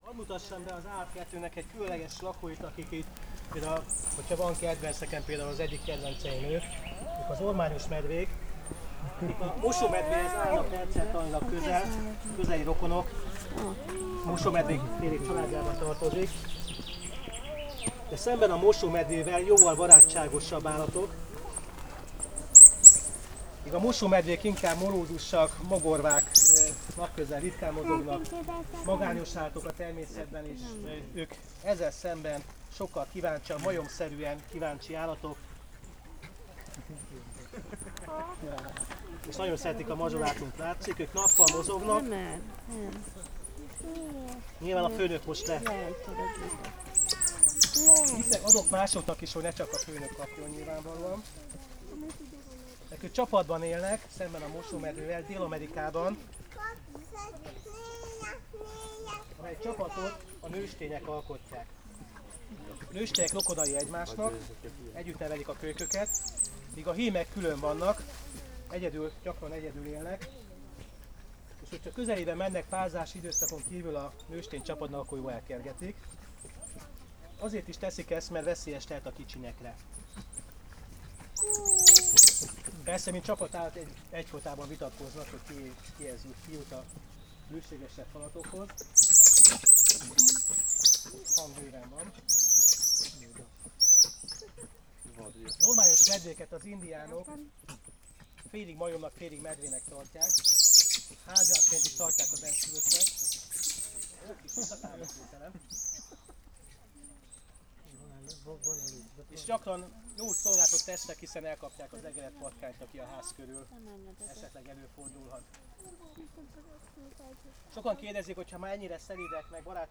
latvanyetetes_zoopedagogus_halk03.54.wav